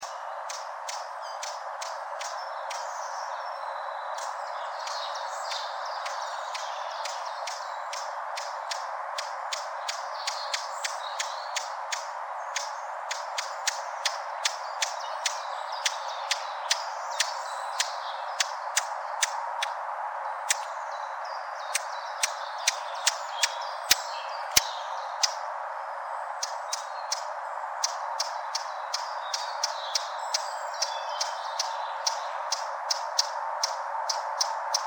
Vögel
mönchsgrasmücke_schimpft.mp3